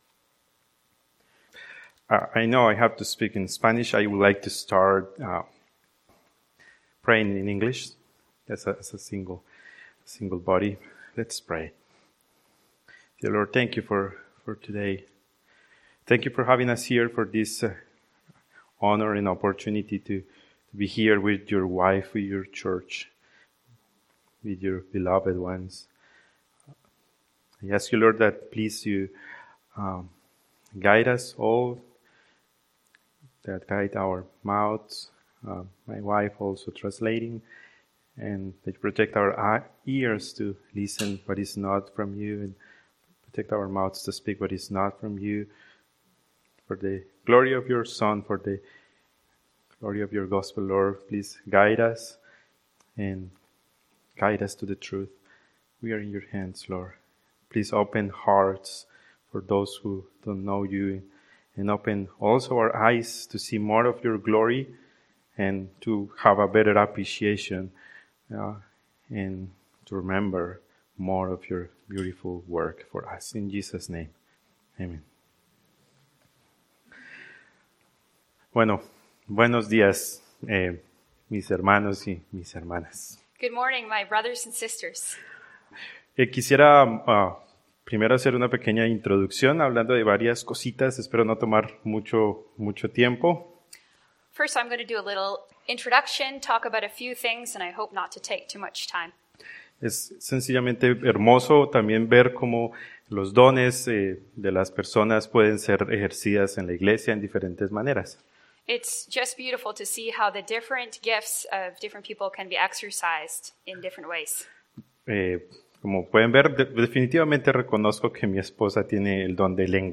Spanish with translation
Sermon